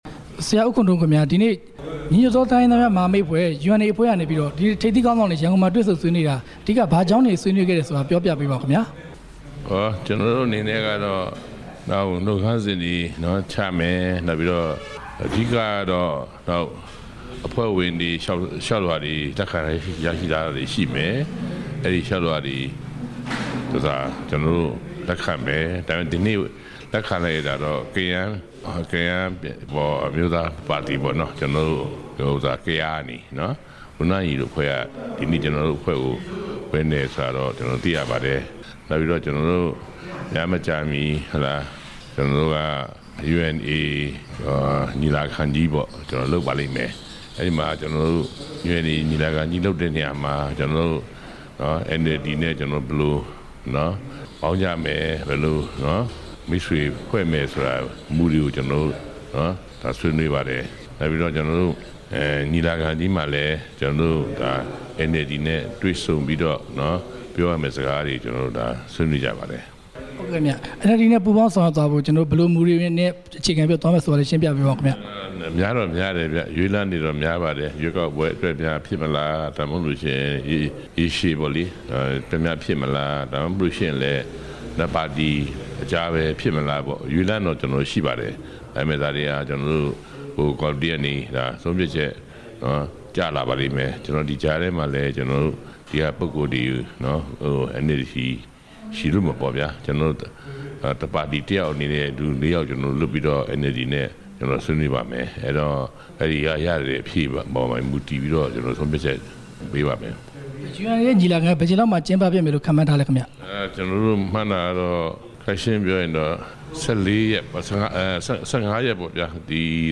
ဦးခွန်ထွန်းဦးကို မေးမြန်းချက်